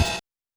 hihat.wav